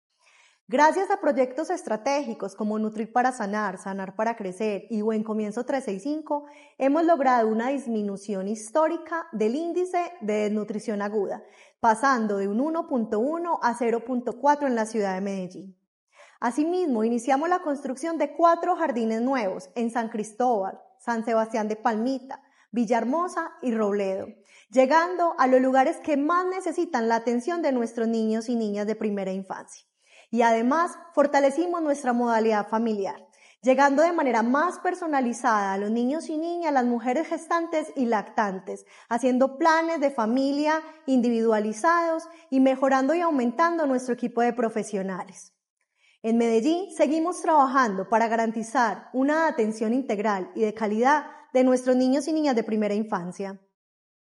Buen Comienzo garantiza entre el 70 % y el 80 % de la alimentación diaria de las niñas y niños matriculados en los centros y jardines. Declaraciones directora de Buen Comienzo, Diana Carmona Una de las apuestas de la Alcaldía de Medellín dentro de la atención integral de la primera infancia, es garantizar la alimentación durante fines de semana, festivos y días de receso.
Declaraciones-directora-de-Buen-Comienzo-Diana-Carmona.mp3